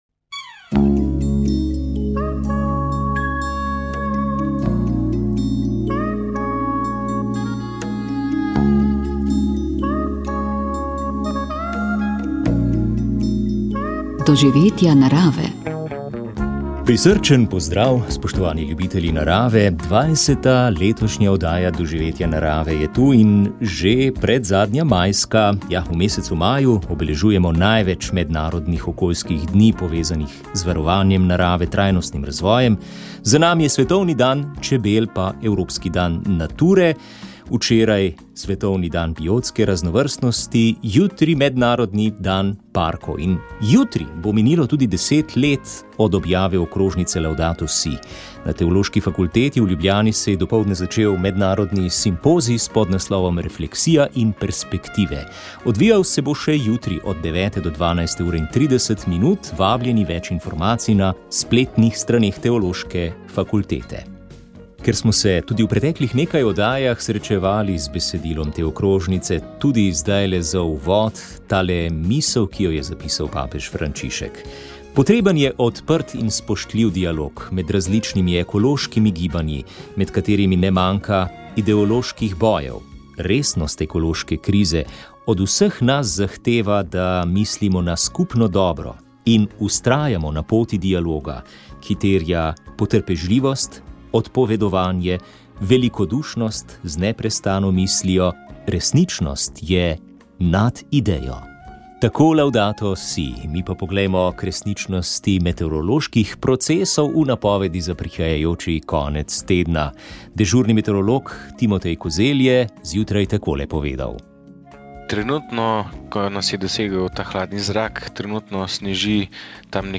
V ta namen je prejšnji teden potekala okrogla miza o tej tako zelo občutljivi temi življenja.